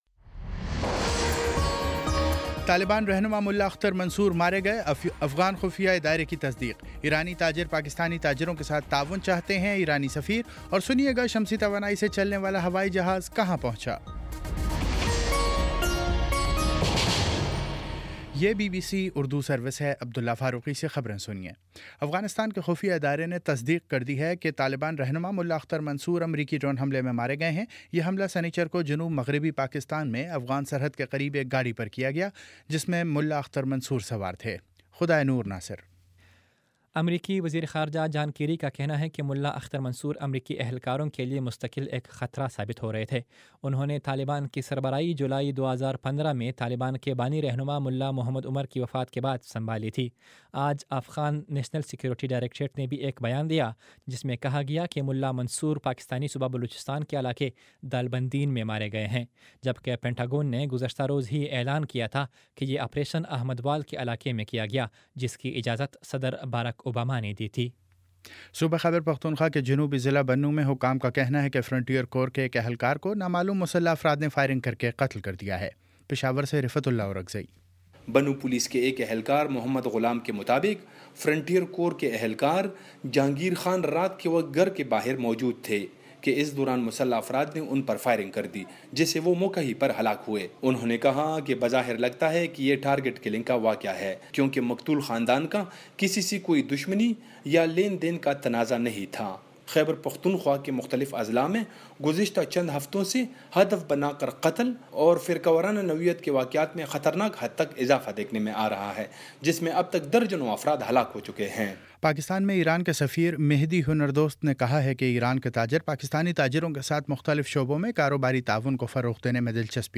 مئی 22: شام سات بجے کا نیوز بُلیٹن